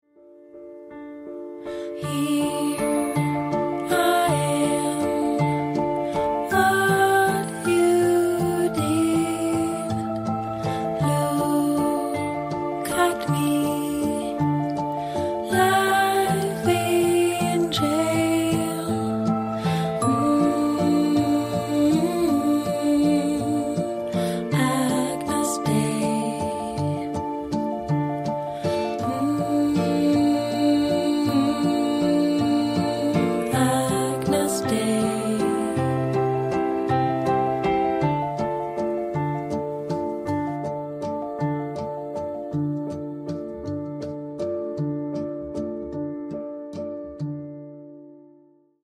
• Качество: 128, Stereo
мелодичные
саундтреки
женский голос
спокойные